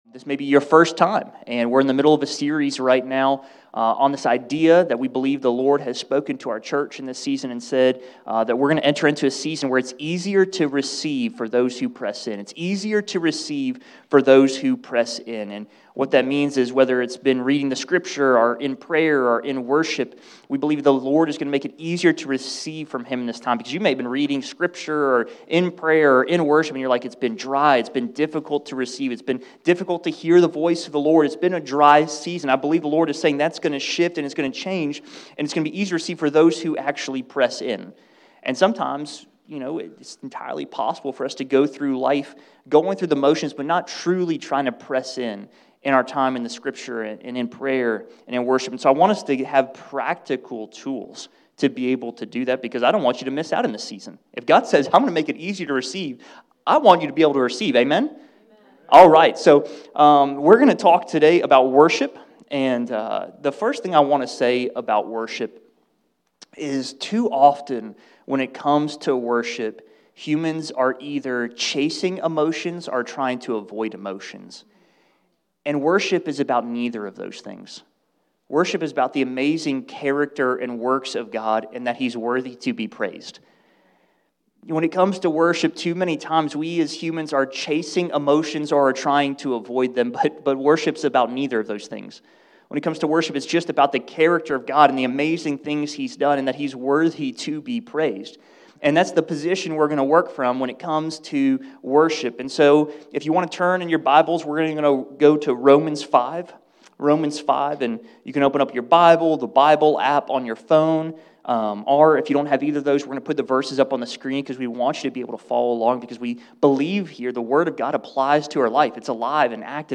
Worship isn’t about either. This week, Pastor teaches on how worship is about the amazing character and works of God and that He is always worthy of praise.